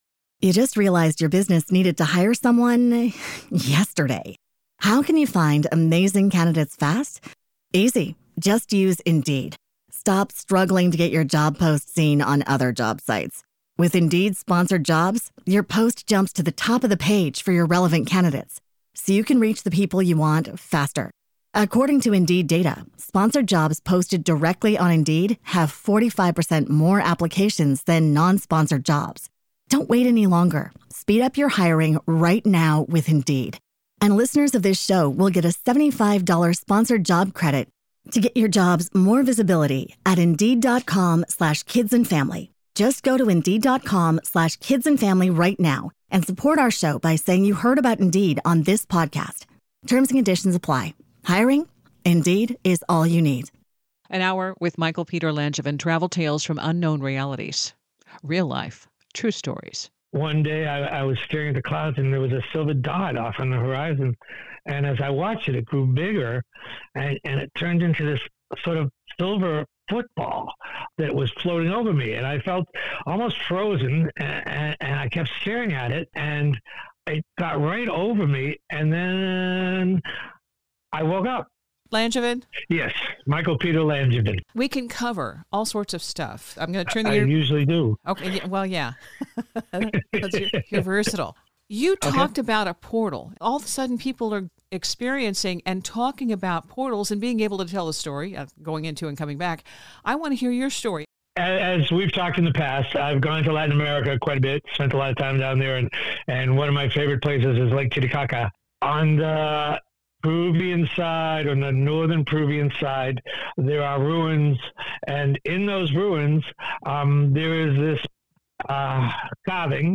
Some of the encounters in this interview include: - Visit to a portal in South America.